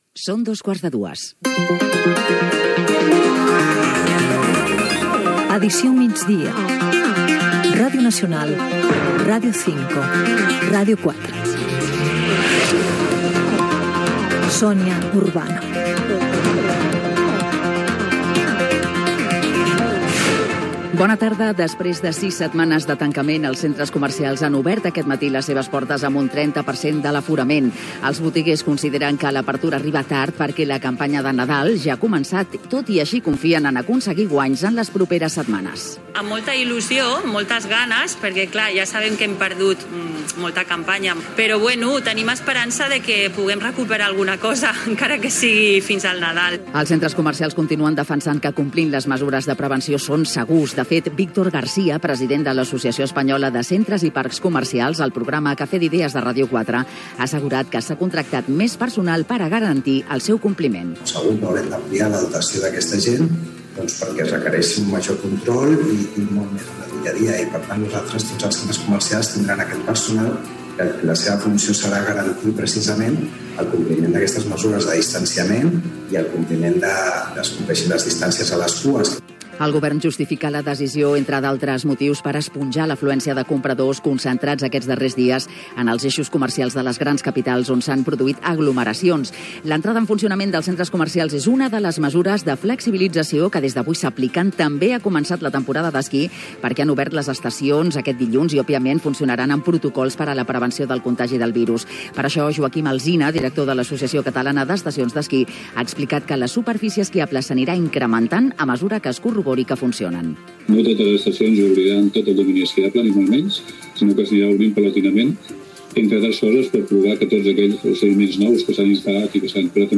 Hora, careta, informació de l'obertura dels centres comercials i les pistes d'esquí després de les restriccions degudes a la pandèmia de la Covid-19. Equip, sumari informatiu, estat del trànsit, el temps, hora i indicatiu del programa
Informatiu